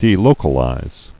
(dē-lōkə-līz)